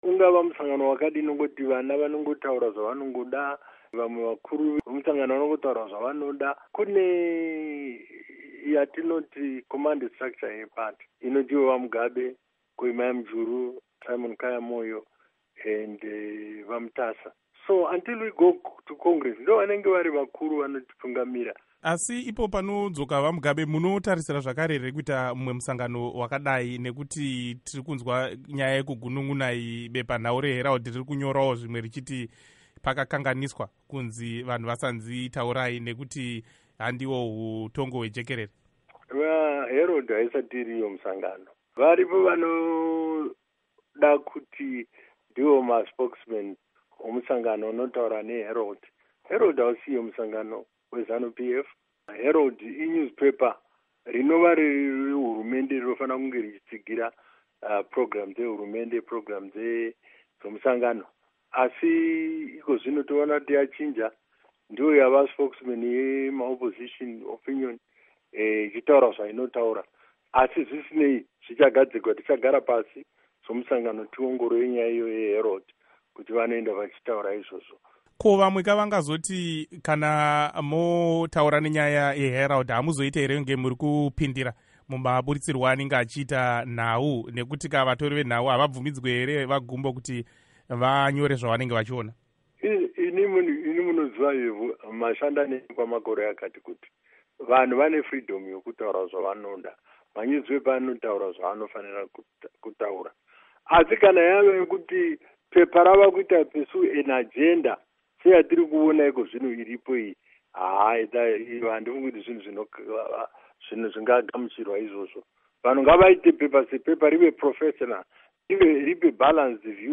Hurukuro naVaRugare Gumbo